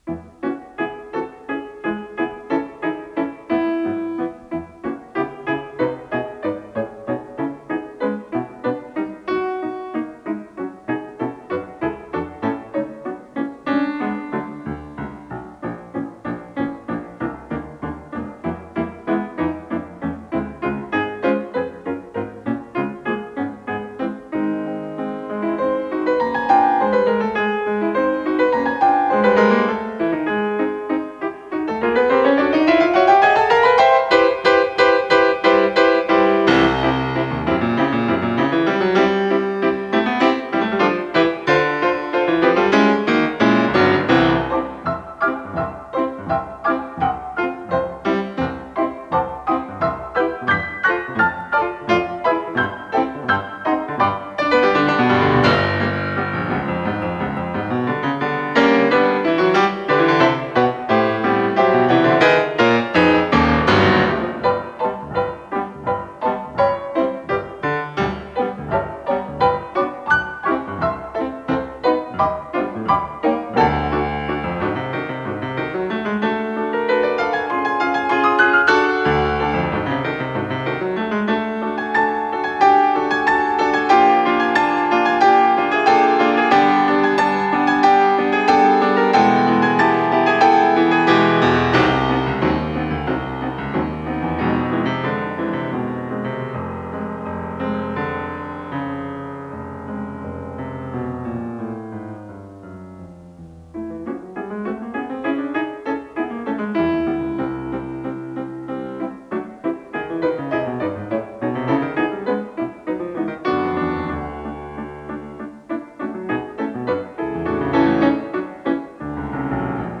с профессиональной магнитной ленты
фортепиано
ВариантДубль моно